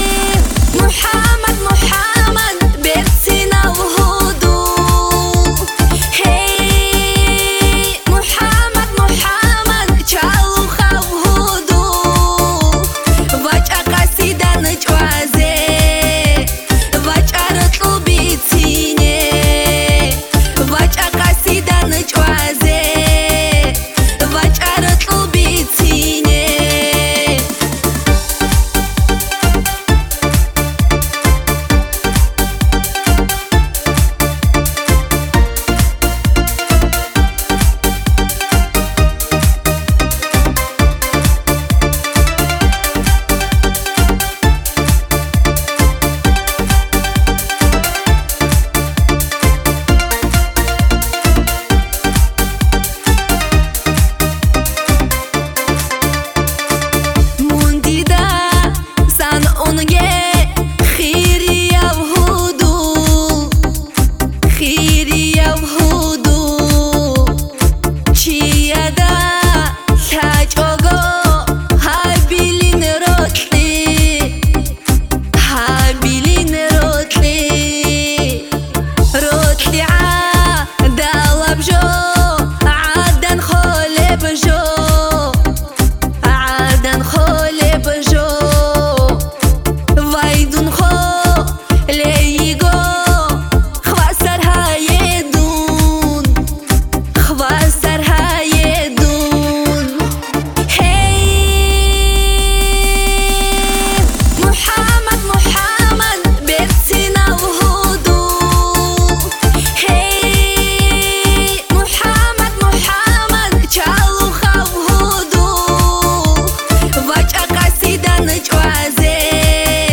поп
кавказские
аварские